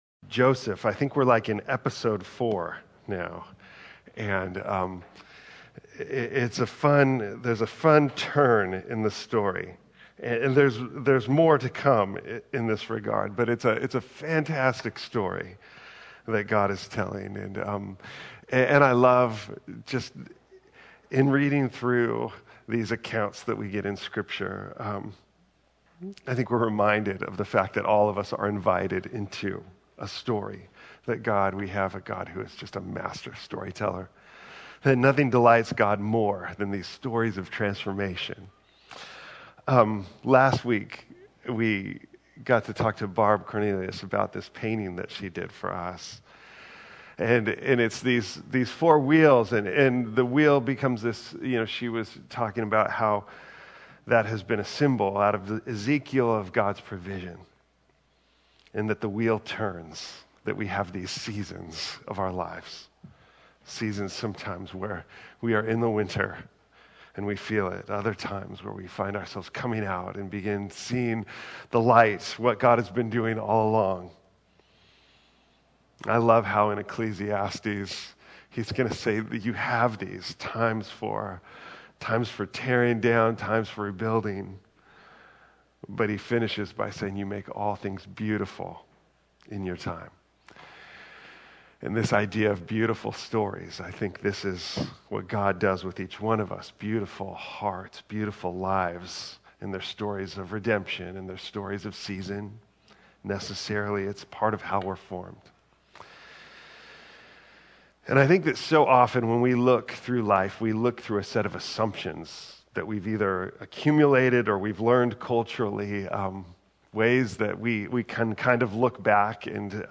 preaches from the story of Joseph in Genesis 41